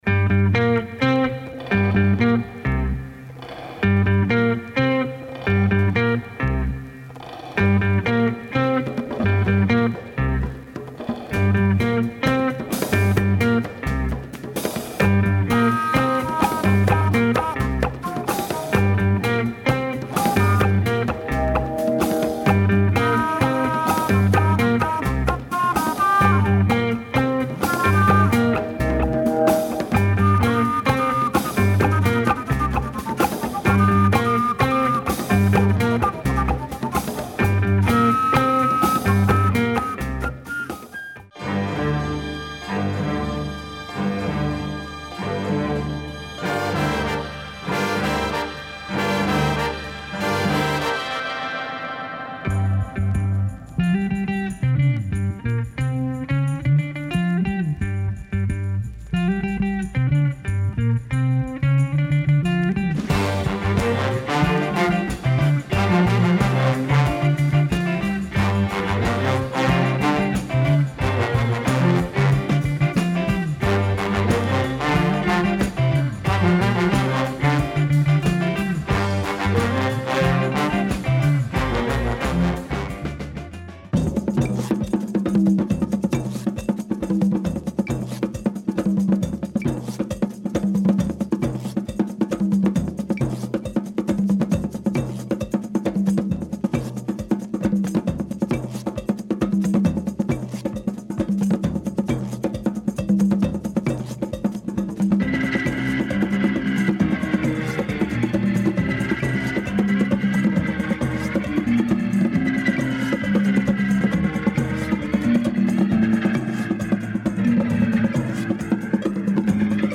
featuring pop jazz and spacy jungle grooves.
Dope organ parts as usual !